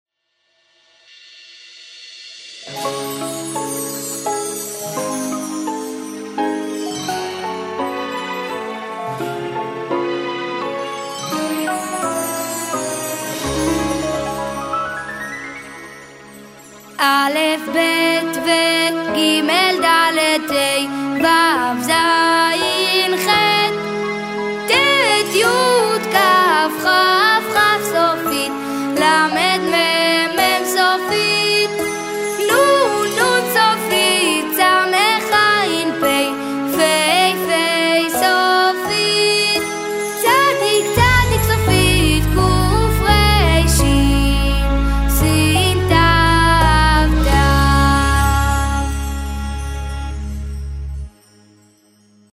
שירים מוקלטים לשינון יומי של שמות האותיות והתנועות תוך מעקב באצבע על לוחות מתאימים.